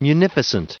added pronounciation and merriam webster audio
522_munificent.ogg